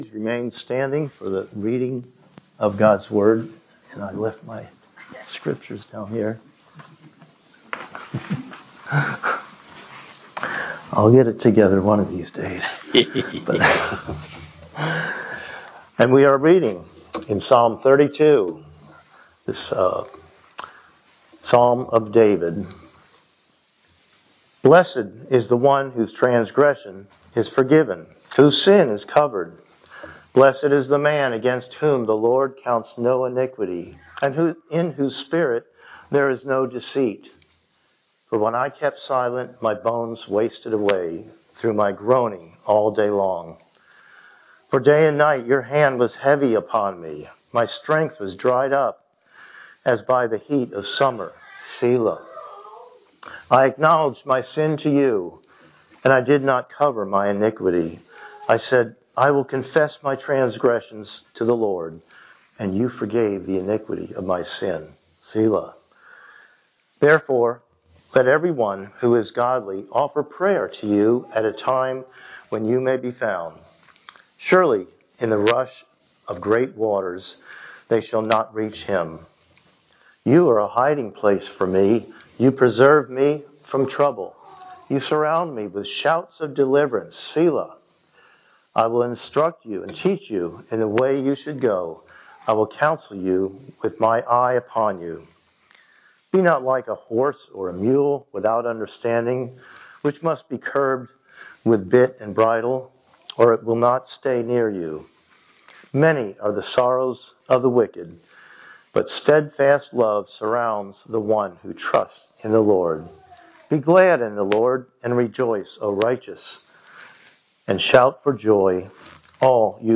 Haggai 2:1-9 Service Type: Sunday Morning Worship